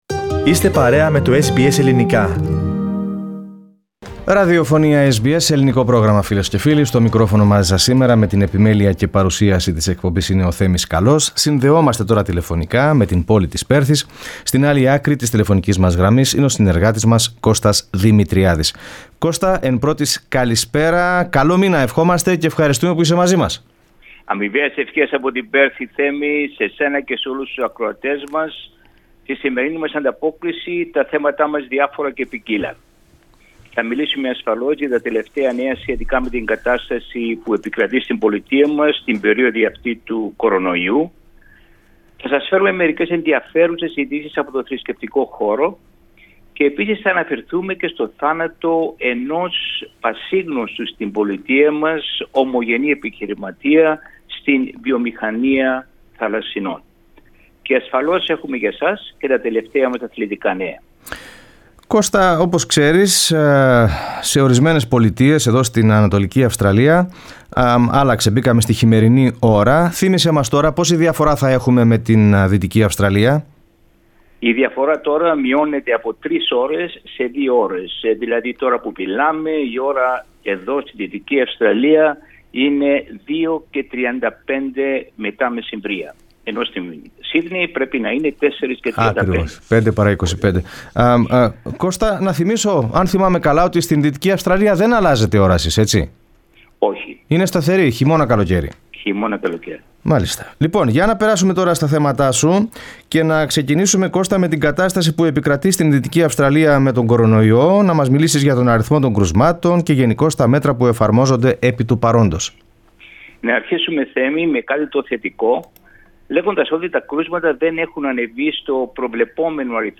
Η εβδομαδιαία ανταπόκριση από την Πέρθη.